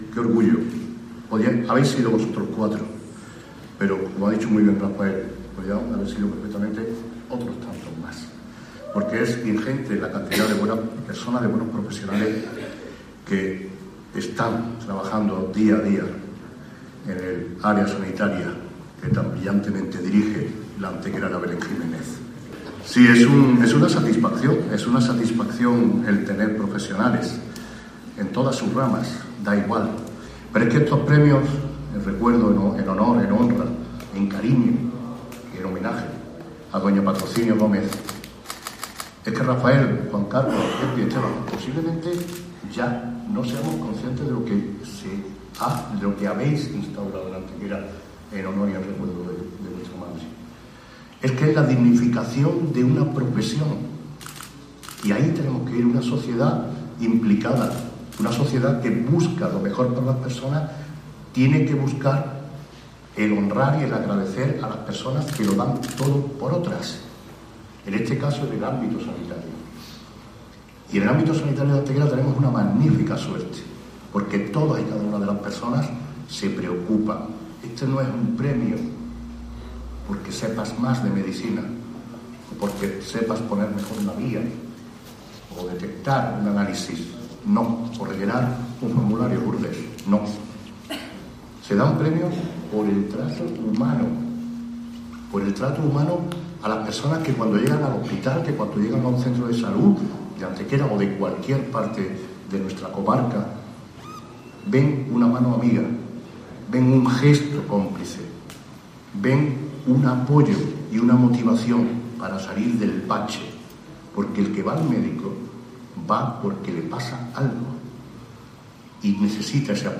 El acto se ha desarrollado en el patio del Edificio Gómez Serrano de la céntrica calle Infante Don Fernando.
Cortes de voz M. Barón 1108.03 kb Formato: mp3